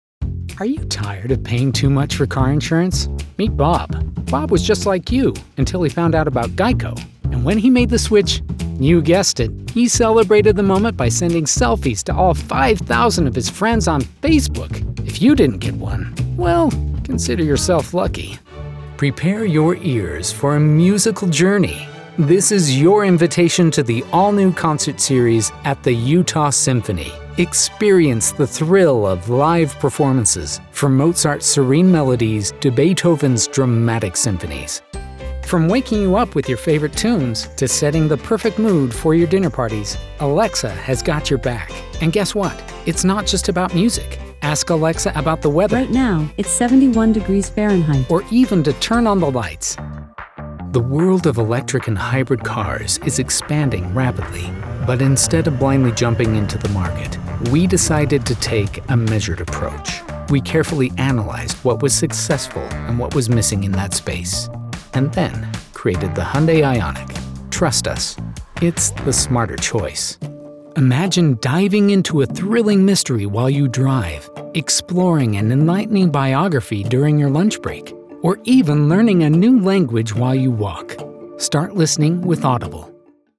Commercial Demo
Engaging, Warm, Professional